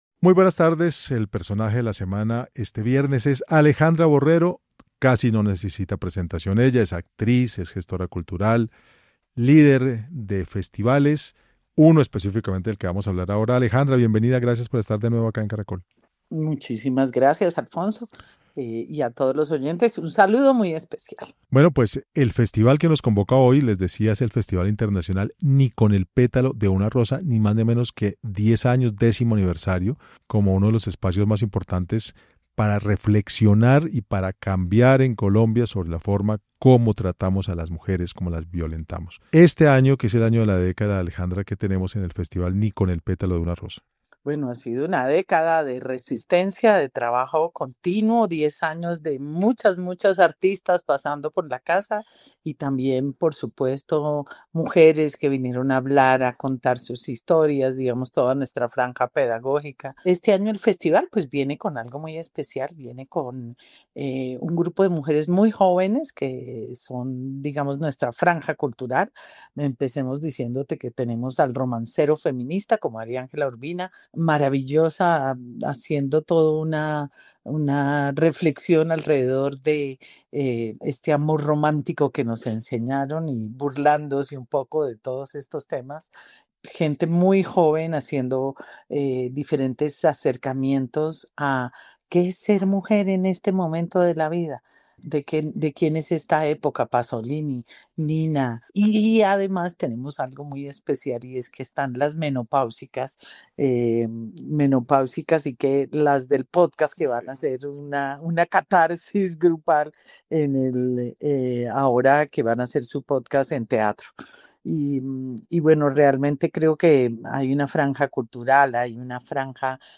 Alejandra Borrero, actriz, gestora cultural y líder del Festival, estuvo en los micrófonos de Caracol Radio hablando sobre la programación y organización de este festival que cumple 10 años desde su fundación.